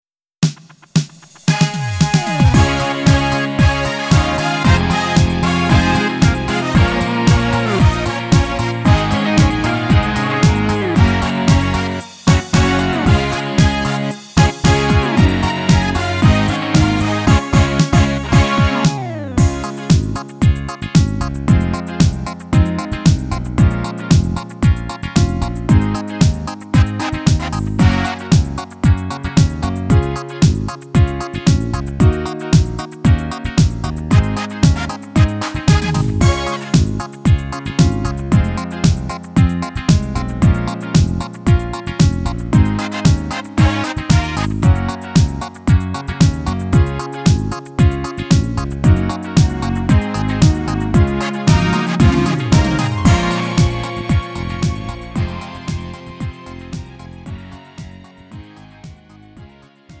음정 -1키 3:05
장르 가요 구분 Lite MR
Lite MR은 저렴한 가격에 간단한 연습이나 취미용으로 활용할 수 있는 가벼운 반주입니다.